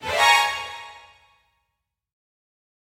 Испуганная скрипка тревожная мелодия внезапной опасности